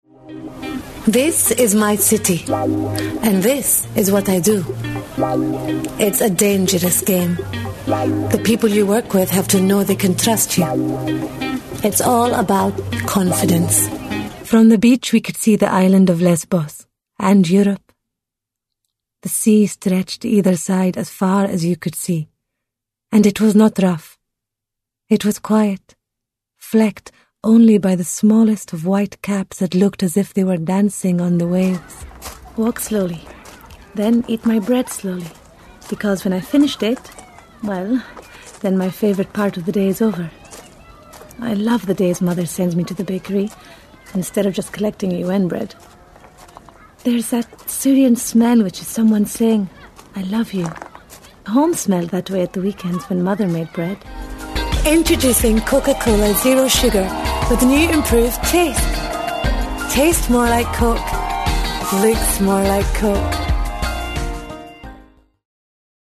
Female / 20s, 30s / Arabic, English, Scottish
Showreel